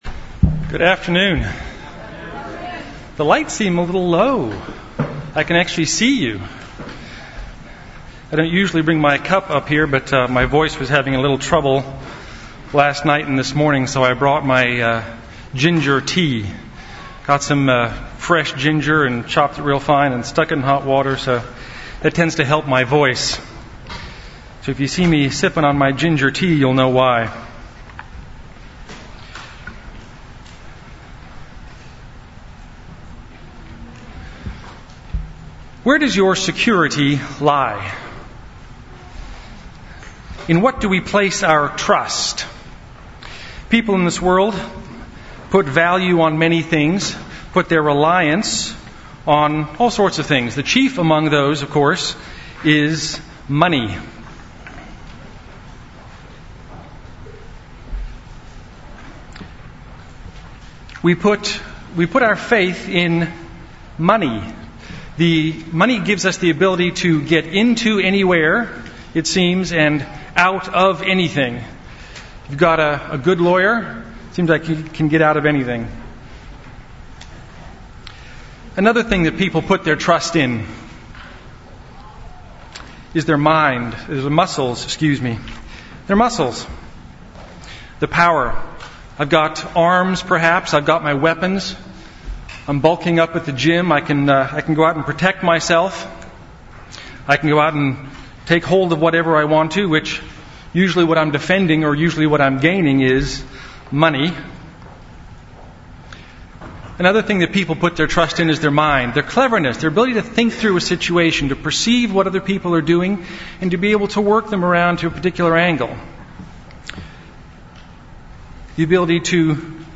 UCG Sermon Studying the bible?
Given in Dallas, TX